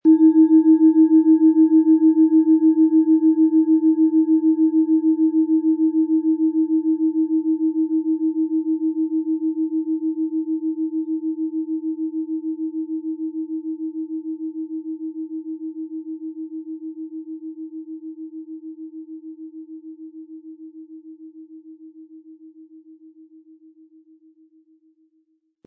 Planetenschale® Zeitlos schwingen und leben & Körper und Seele verbinden mit Wasserstoffgamma, Ø 14,5 cm, 500-600 Gramm inkl. Klöppel
Lieferung inklusive passendem Klöppel, der gut zur Planetenschale passt und diese sehr schön und wohlklingend ertönen lässt.
MaterialBronze